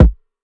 Vanilla Sky Kick.wav